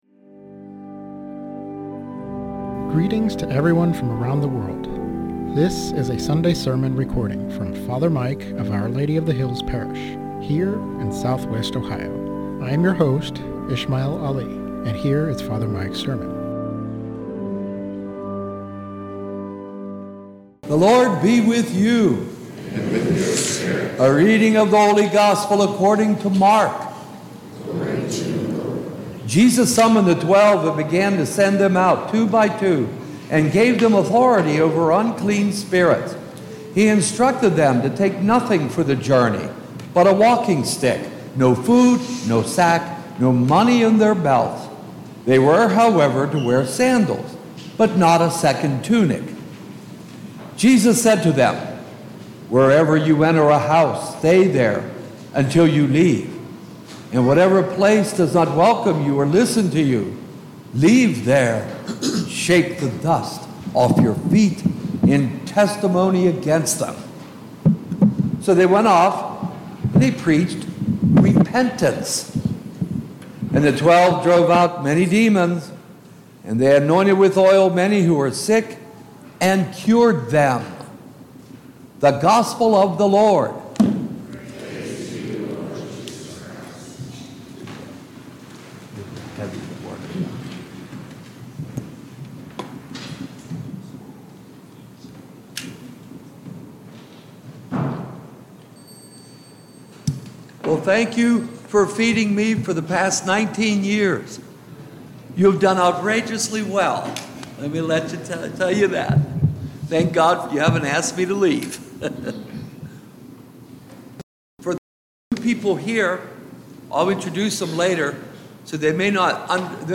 SERMON ON MARK 6:7-13